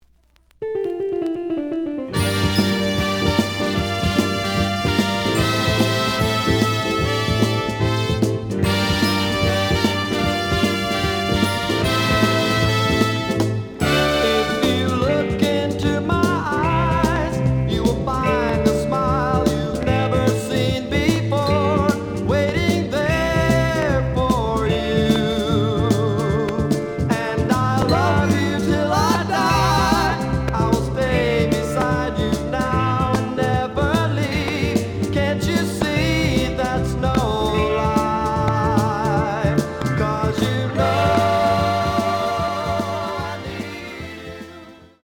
The audio sample is recorded from the actual item.
●Genre: Soul, 70's Soul
Some click noise on both sides due to scratches.